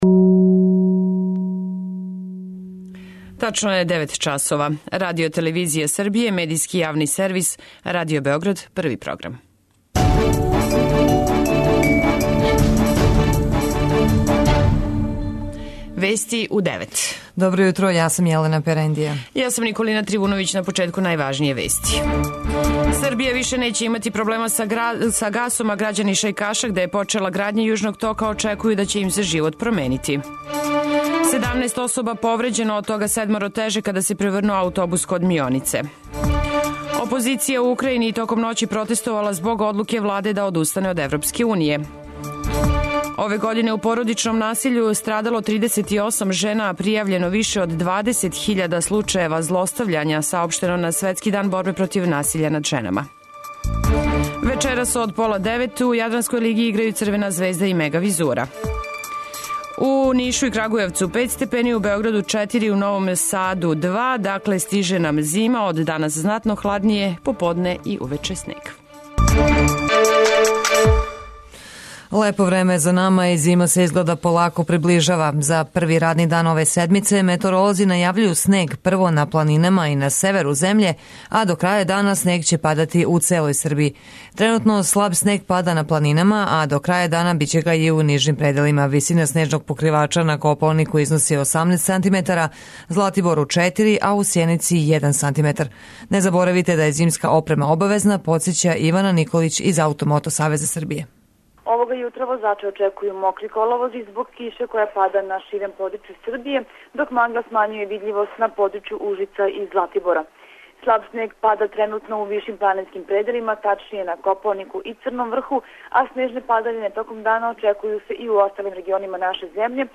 Уреднице и водитељке